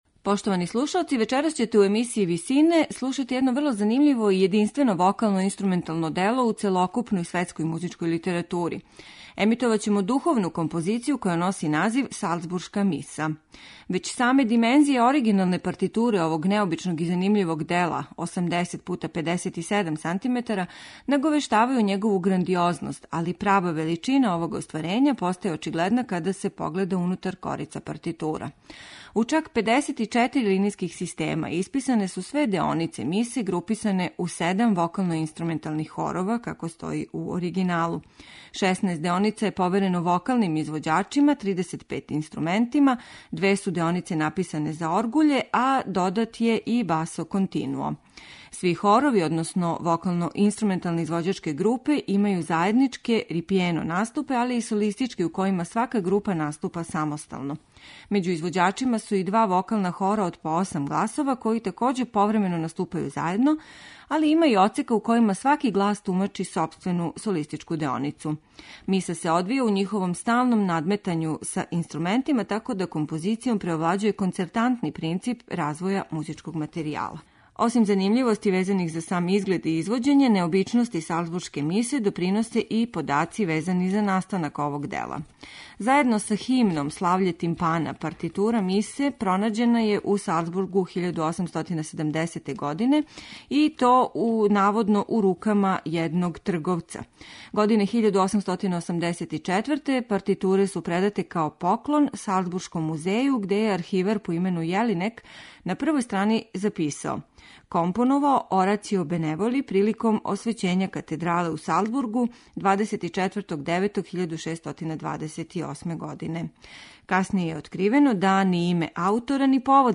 Такозвану Салцбуршку мису слушаћете вечерас у извођењу хора Есколанија де Монсерат, дечјег хора из Телцера и ансамбла Колегијум Аурем, чији чланови свирају на оригиналним инструментима.